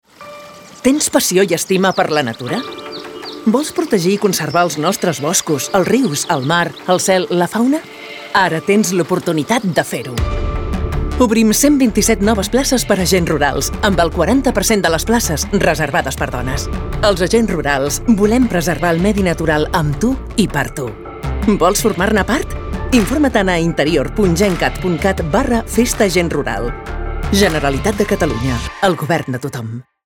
Falca_radio_Convocatoria Agents Rurals.mp3